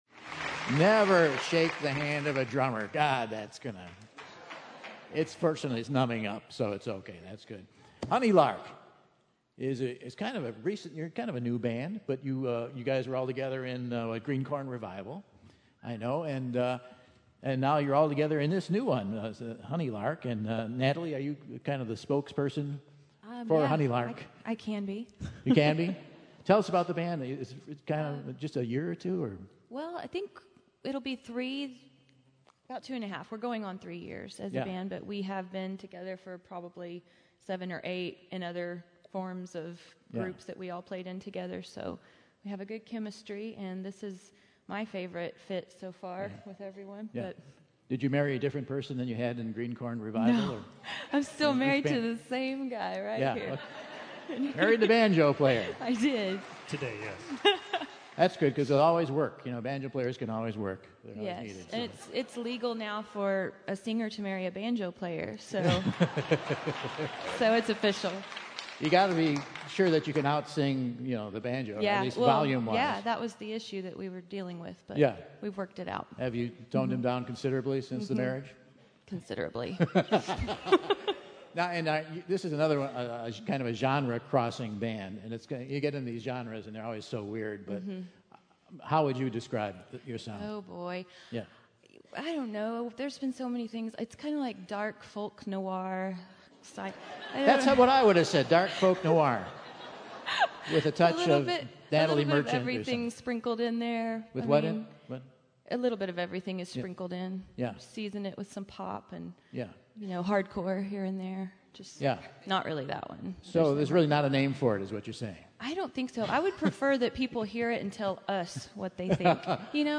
dark, sticky-sweet offering